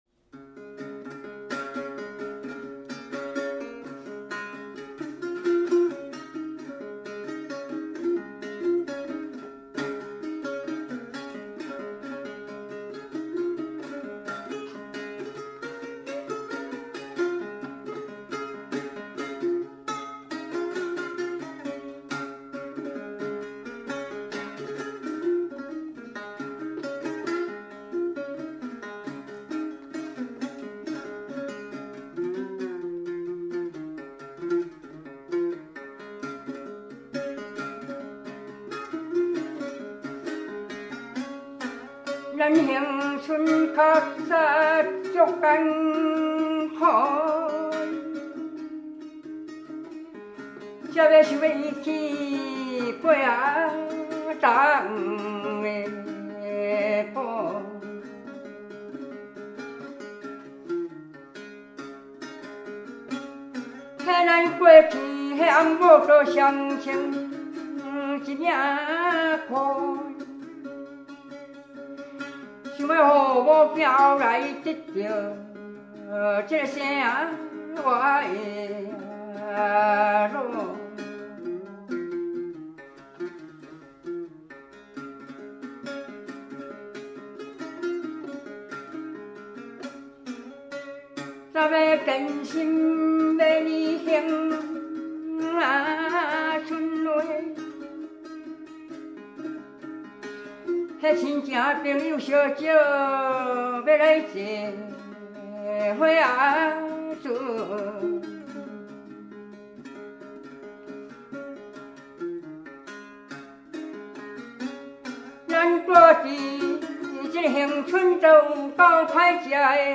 ◎制作群 ： 演出：月琴,壳仔弦｜
台湾民歌
18首原汁原味的素人歌声，在粗哑中满怀真性情，那是上一代的回忆，这一代的情感，下一代的宝藏！